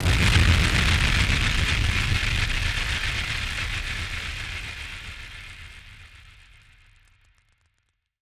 BF_DrumBombC-08.wav